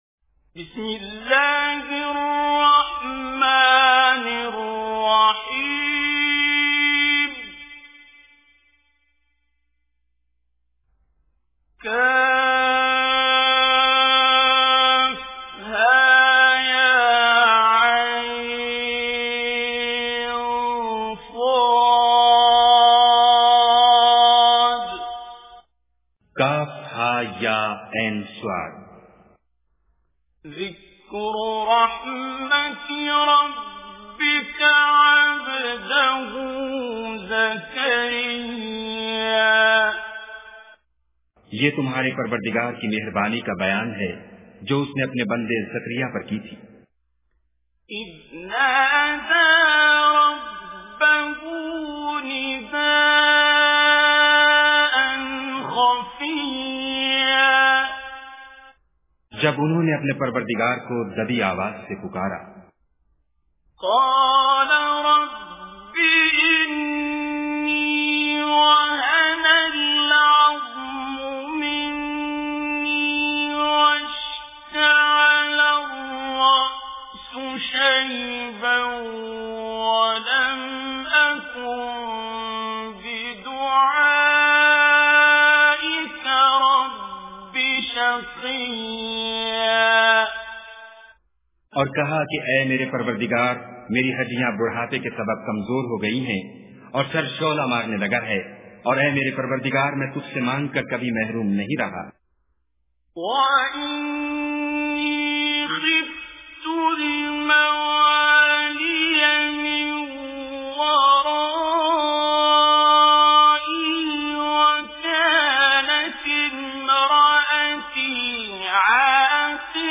Surah Maryam Tilawat with Urdu Translation